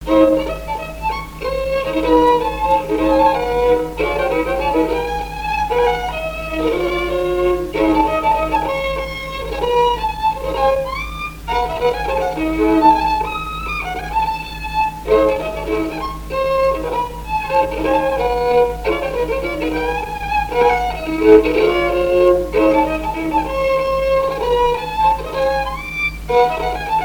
Boupère (Le) ( Plus d'informations sur Wikipedia ) Vendée
danse : mazurka
Pièce musicale inédite